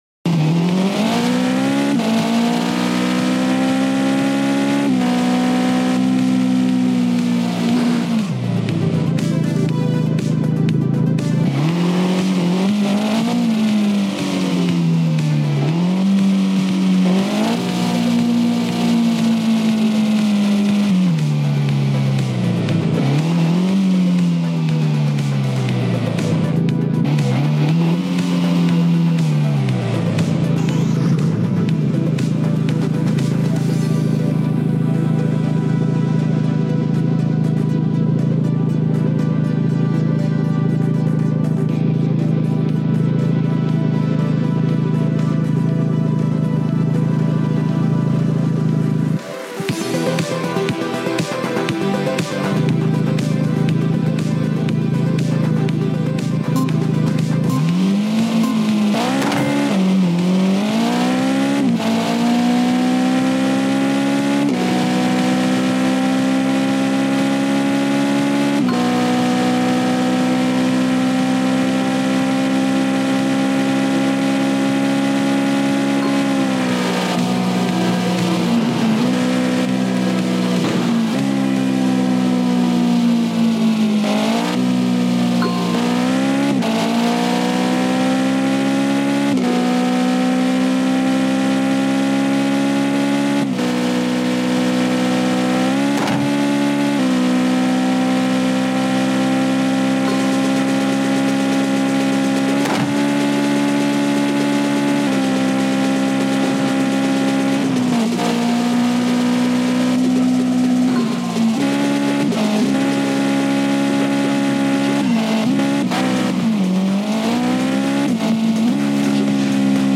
Car Gameplay | Ultimate Driving